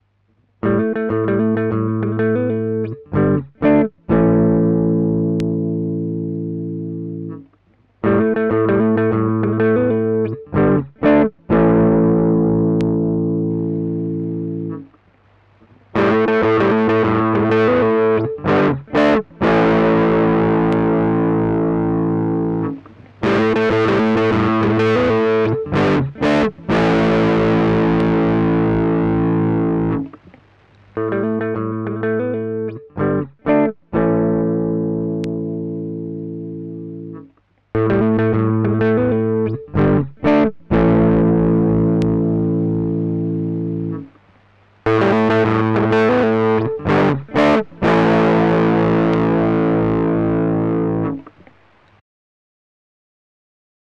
Meet Spark Gap 2, a low-voltage tube distortion pedal that can deliver much higher levels of flame and a crisp tone, unlike the fart-under-a-blanket you get from most starved tube circuits!
I'm not really set up for recording at home anymore, so I used a looper pedal to record a riff, then brought it to my work computer. But I ended up recording a click over it somewhere, and then half way through trying to figure out how to use Reaper it somehow cut off the start of my riff!
Anyway, here's a crappy sound clip at various settings.
I suppose the sample was made with the tone flat and varying the gain level.